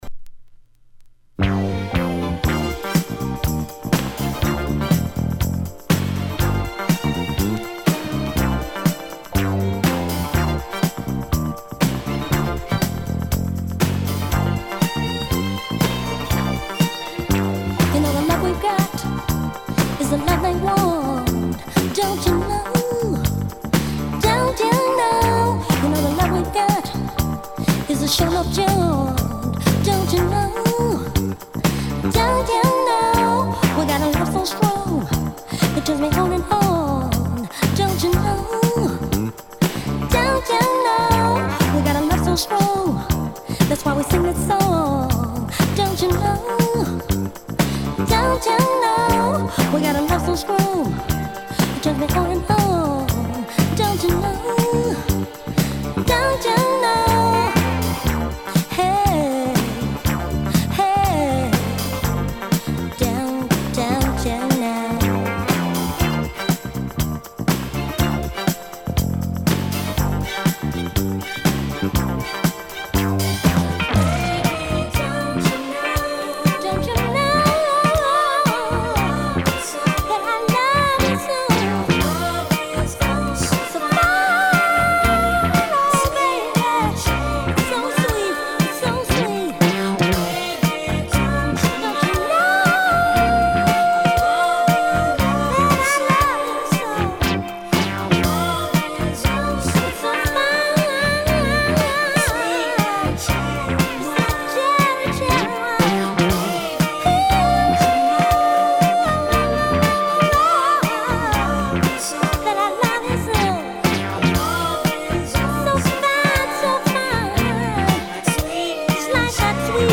Filed under blabla, funk